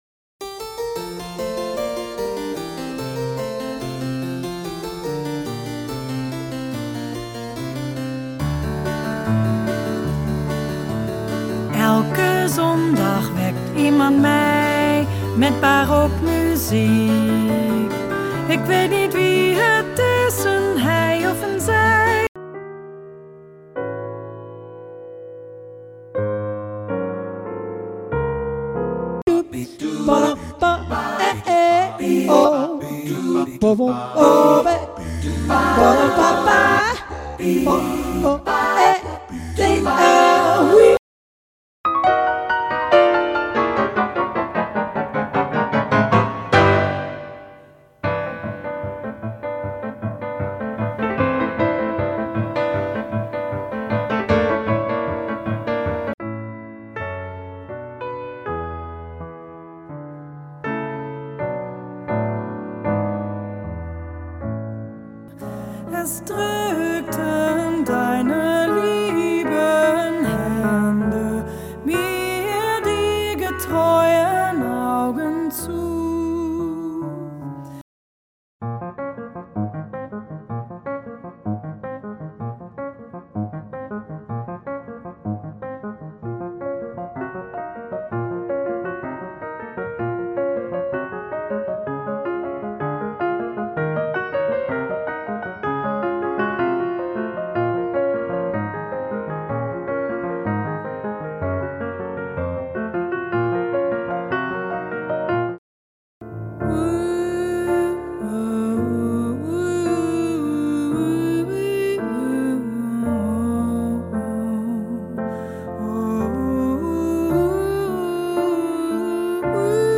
een geweldige klassieke pianist
een geweldige (jazz) zangeres
Met bekende stukken uit uiteenlopende genres.